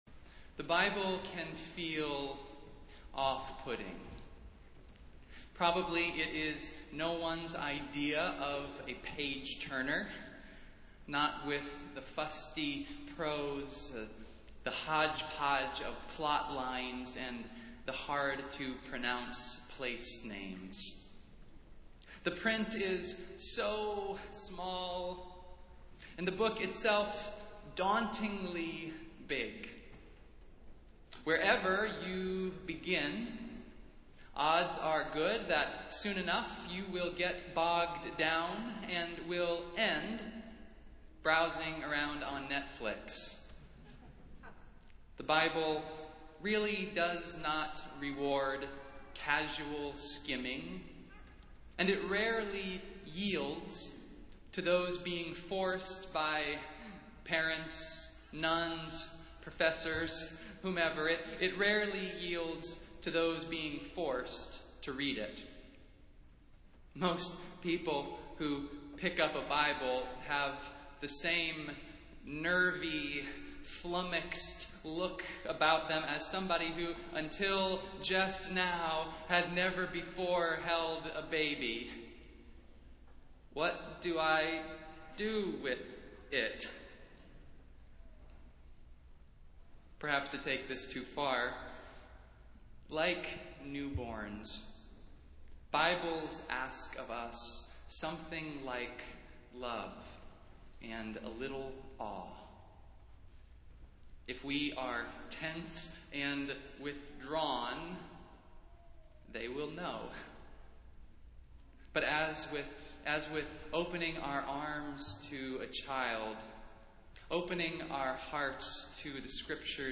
Festival Worship - First Sunday in Advent